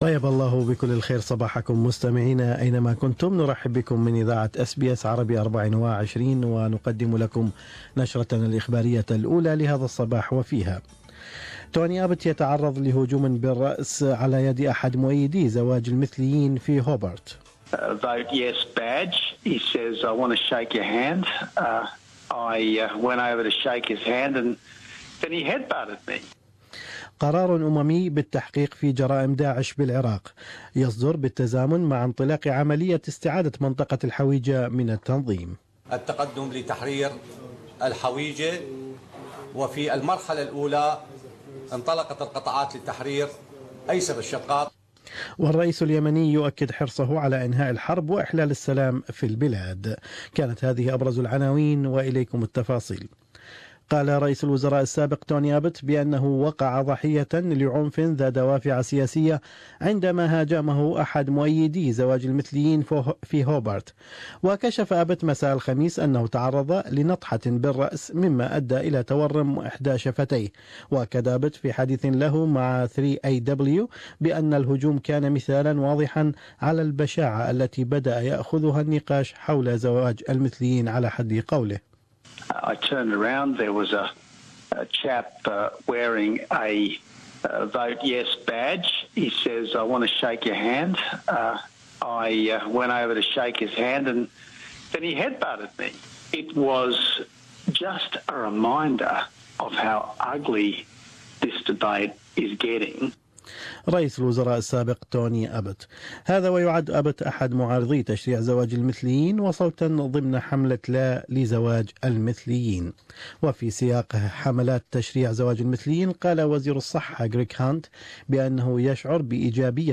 News Bulletin: Abbott Says Headbutted by Yes Campaigner